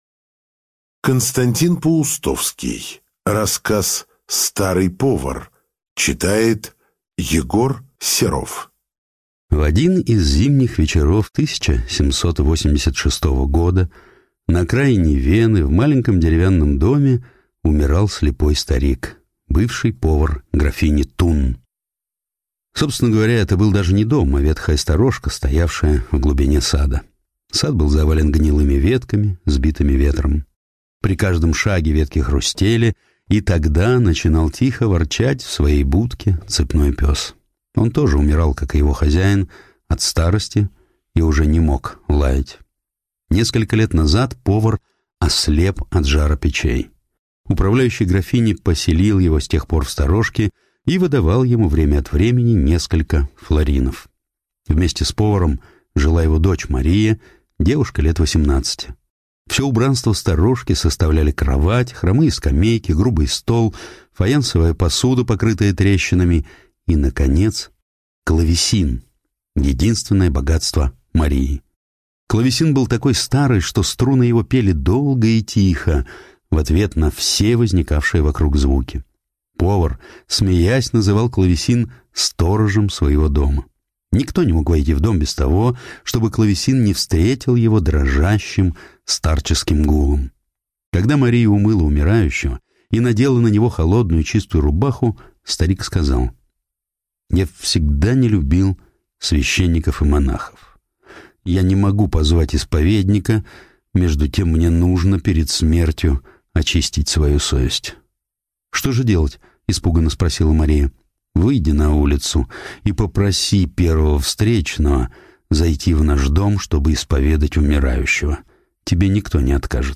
Старый повар - аудио рассказ Паустовского К. Рассказ про старого умирающего повара, последнюю исповедь которого принял Моцарт.